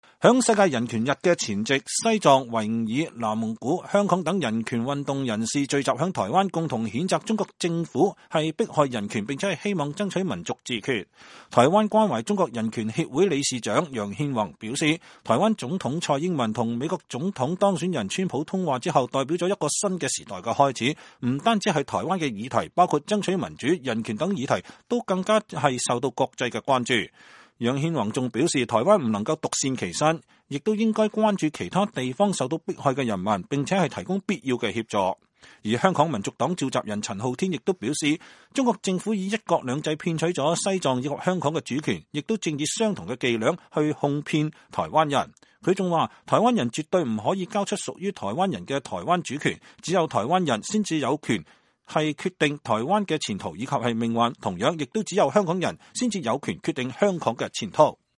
台灣人權團體星期五舉行一場名為“亞洲人權迫害與自決”國際記者會，邀請受到中國當局迫害的西藏、維吾爾、南蒙古、香港等人權運動人士發表看法。